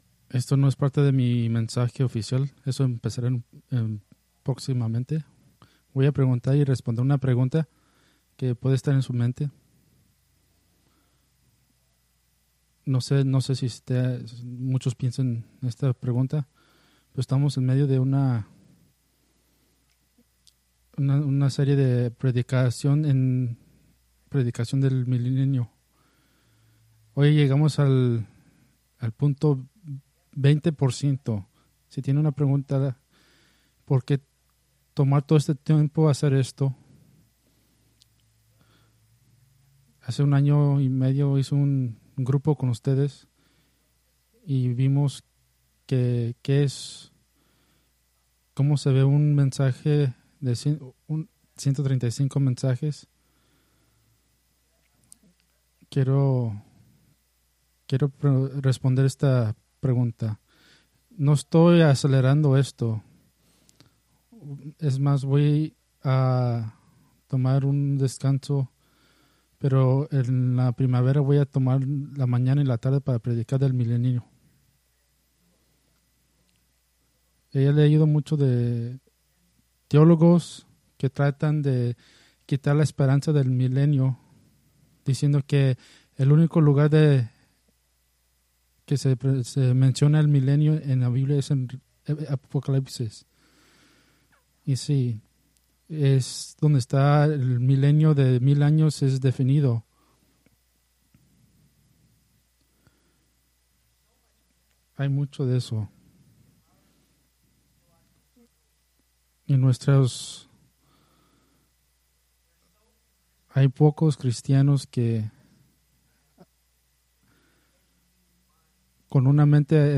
Preached September 1, 2024 from Escrituras seleccionadas